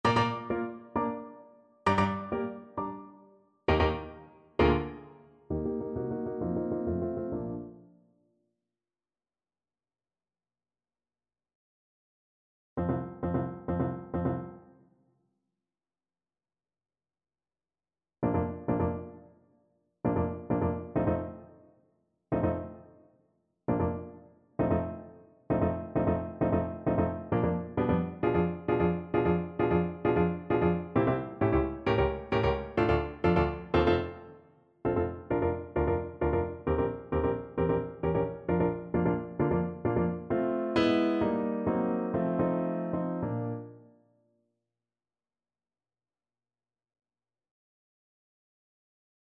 2/2 (View more 2/2 Music)
Classical (View more Classical Clarinet Music)